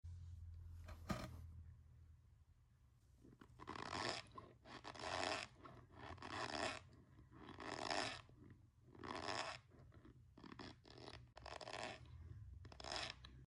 Quick squeaks while my chunks sound effects free download
*ASMR PURPOSE ONLY , DO NOT CONSUME* Quick Squeaks While My Chunks Sound Effects Free Download.